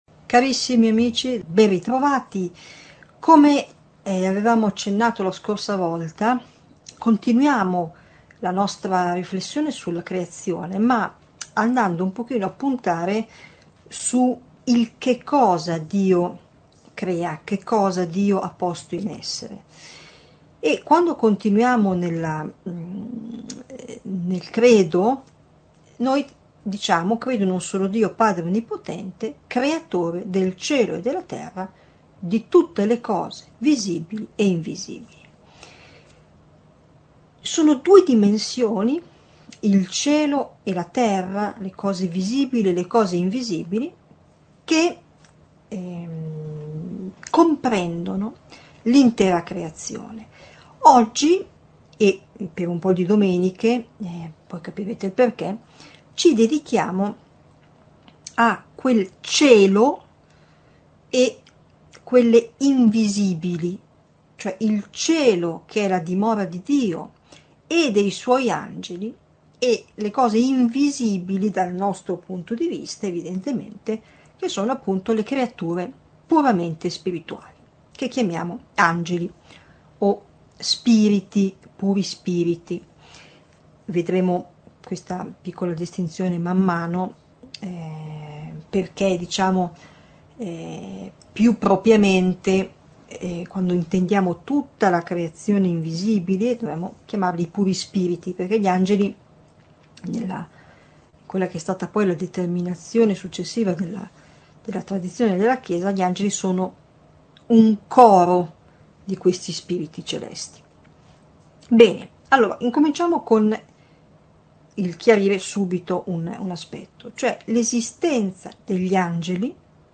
Catechesi adulti